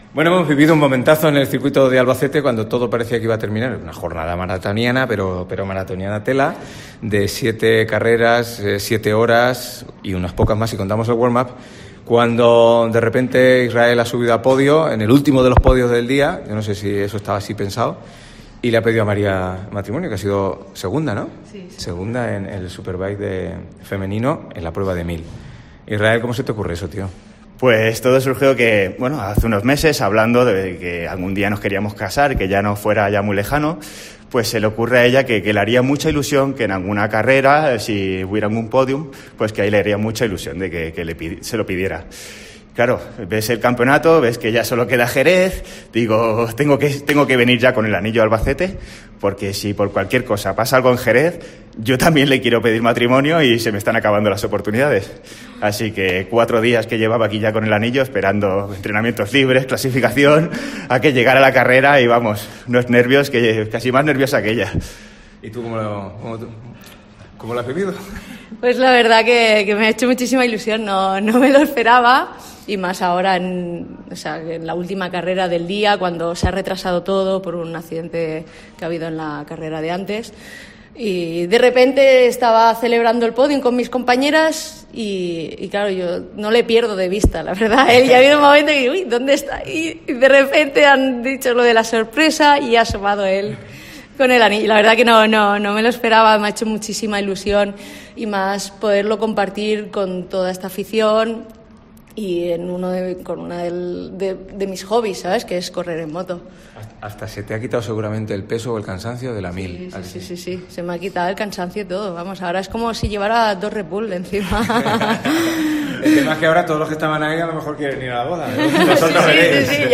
Pedida de mano en el Circuito de Albacete